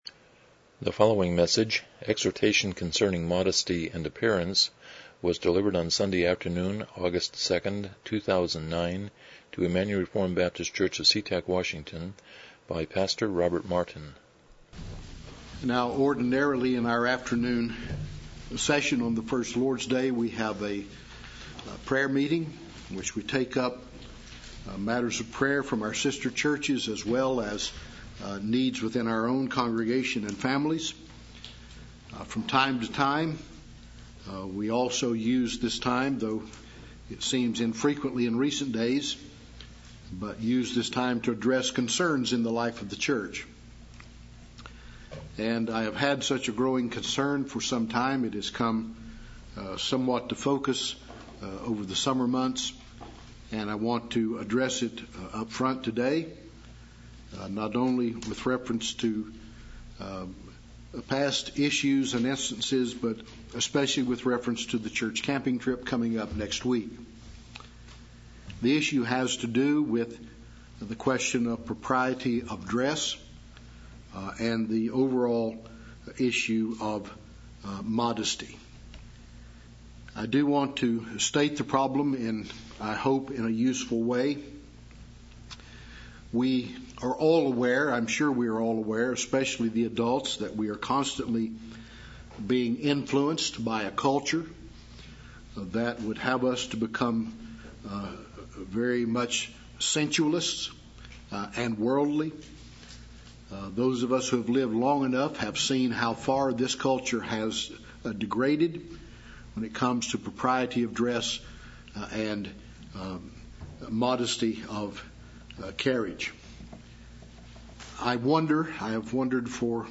Service Type: Evening Worship